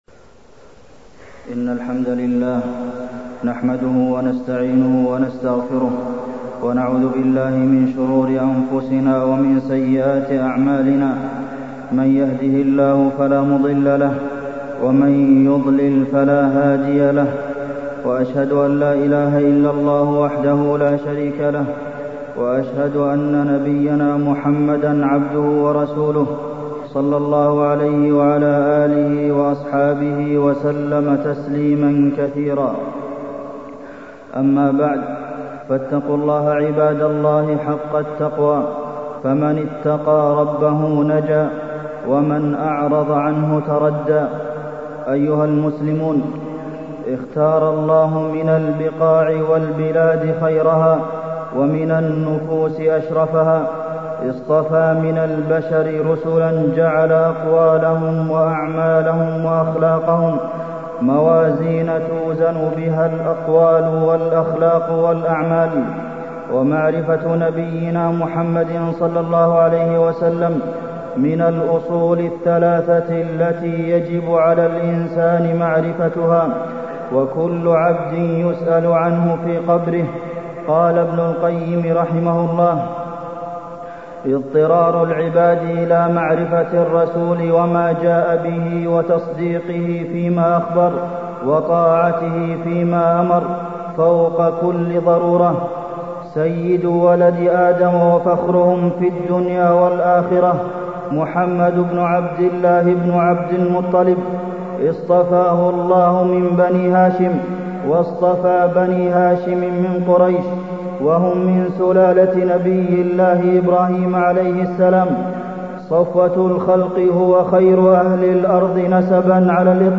تاريخ النشر ٢٧ شوال ١٤٢٥ هـ المكان: المسجد النبوي الشيخ: فضيلة الشيخ د. عبدالمحسن بن محمد القاسم فضيلة الشيخ د. عبدالمحسن بن محمد القاسم محبة الرسول صلى الله عليه وسلم